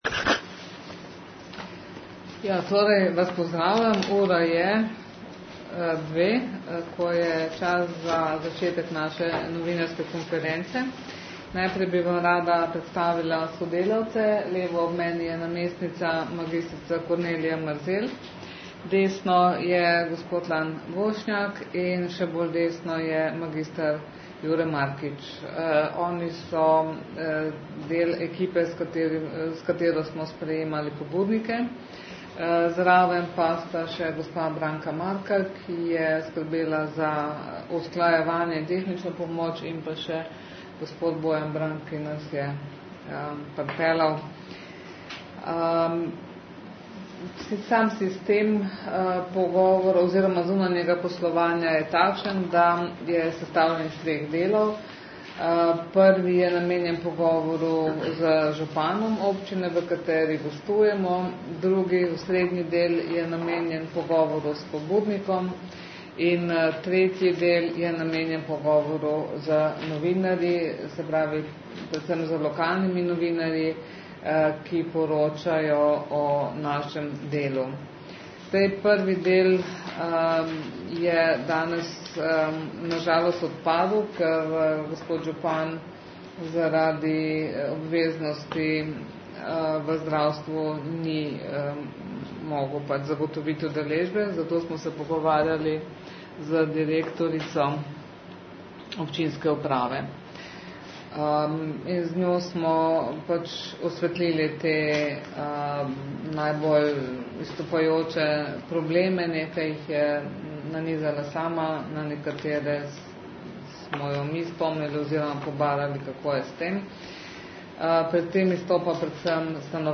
Po opravljenih pogovorih je sledila novinarska konferenca.